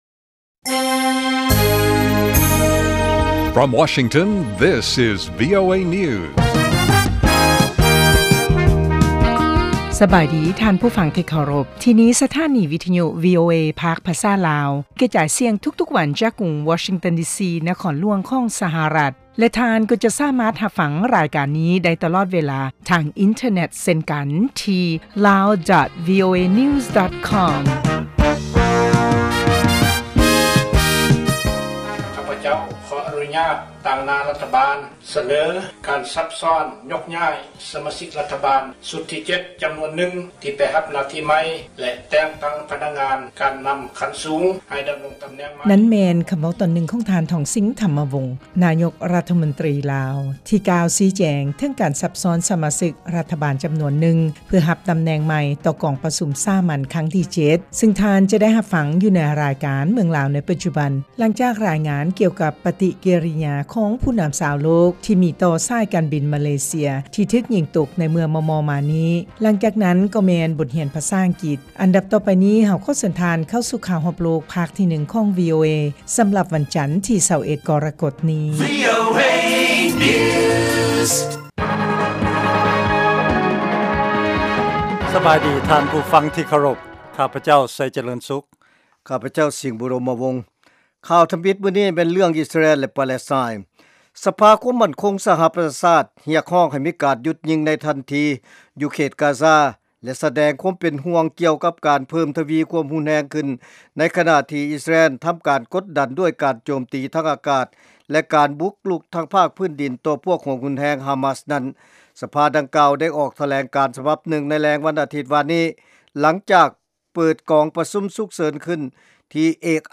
ວີໂອເອພາກພາສາລາວ ກະຈາຍສຽງທຸກໆວັນ ເປັນເວລາ 30 ນາທີ. ພວກເຮົາສະເໜີຂ່າວ ຂໍ້ມູນ ແລະລາຍງານທີ່ໜ້າສົນໃຈ ກ່ຽວກັບເຫດການທີ່ເກີດຂຶ້ນໃນປະເທດລາວ ສະຫະລັດອາເມຣິກາ ເອເຊຍແລະຂົງເຂດອື່ນໆຂອງໂລກ ຕະຫລອດທັງບົດຮຽນພາສາອັງກິດ ແລະລາຍການເພງຕາມຄຳຂໍຂອງທ່ານຜູ້ຟັງ. ຕາລາງເວລາອອກອາກາດ ທຸກໆມື້ ເວລາທ້ອງຖິ່ນໃນລາວ 07:30 ໂມງແລງ ເວລາສາກົນ 1230 ຄວາມຍາວ 00:30:00 ຟັງ: MP3